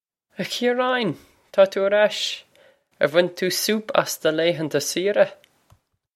Ah Kheer-aw-in! Taw too air ash! Air wan soup oss duh lay-han-ta see-ra?
This is an approximate phonetic pronunciation of the phrase.